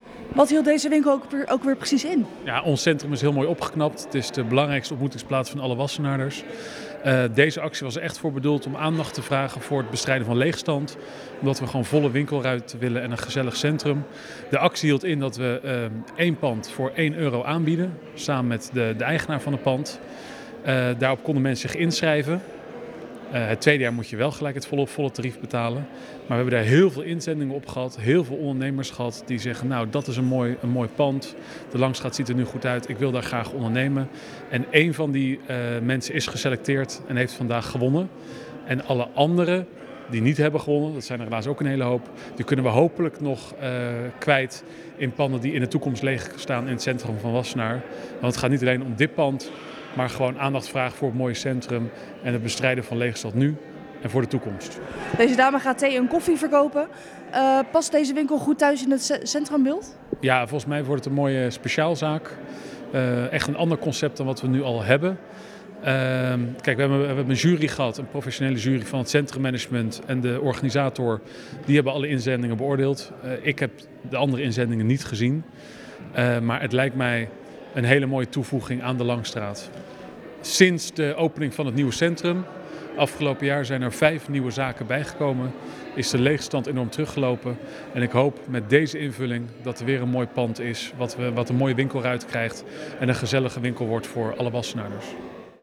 in gesprek met wethouder Laurens van Doeveren.